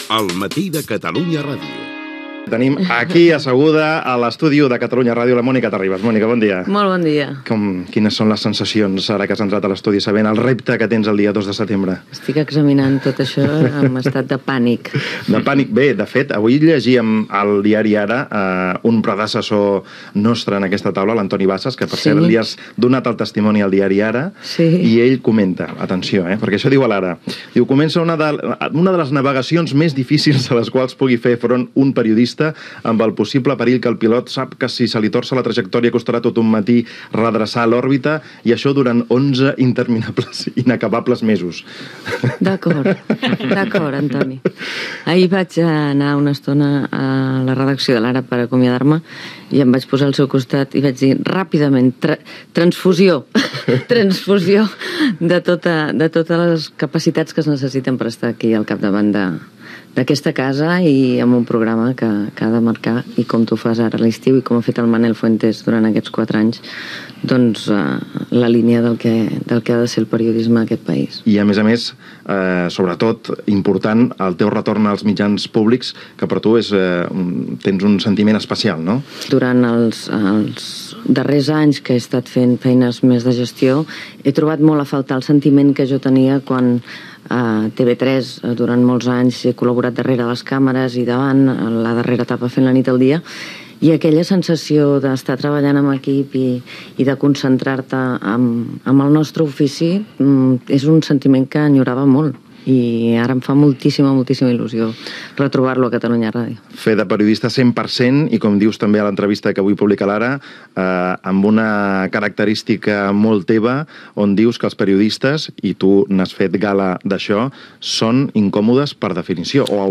Info-entreteniment
Entrevista a Mònica Terribas, que el 2 de setembre de 2013 començava a presentar "El matí de Catalunya Ràdio"